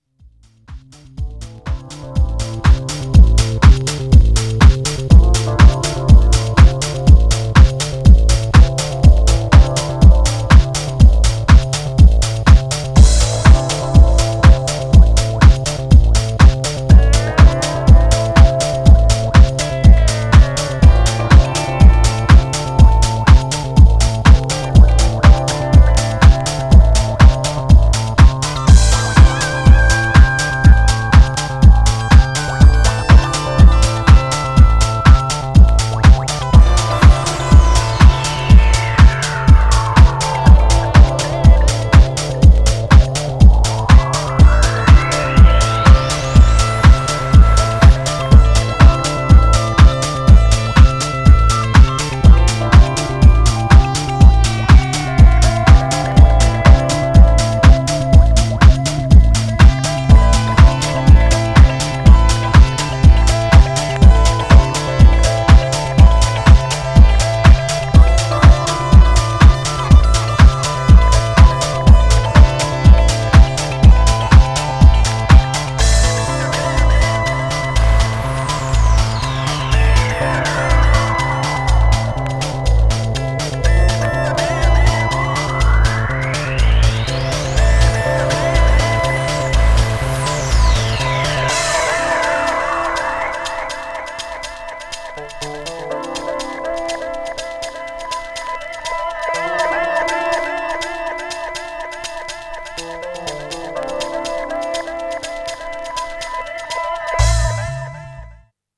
Styl: House